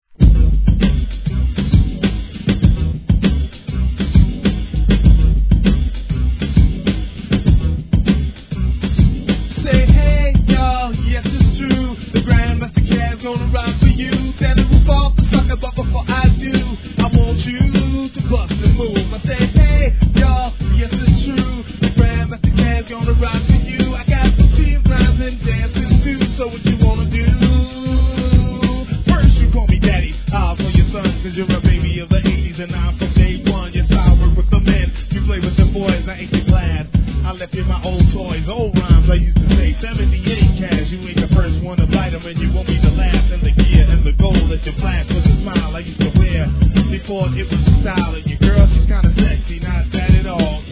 HIP HOP/R&B
を使用したビートのイントロから破壊力大!!